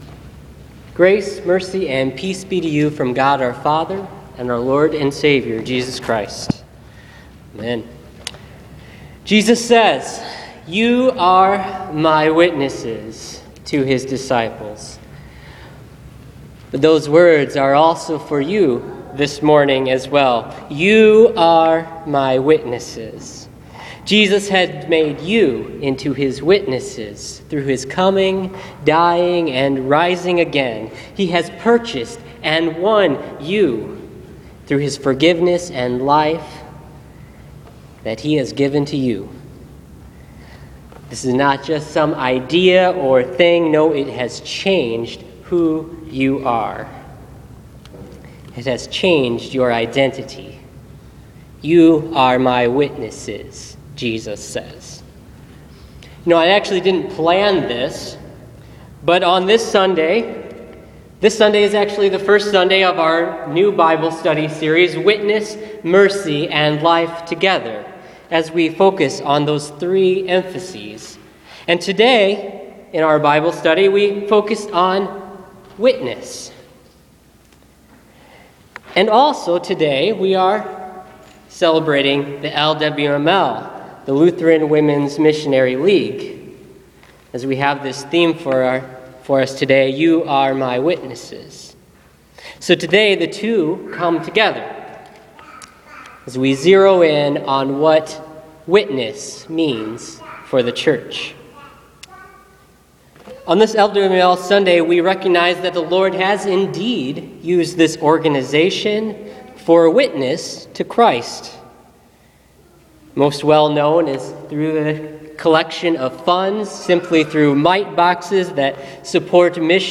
Listen to this week’s sermon on Luke 24:44-49.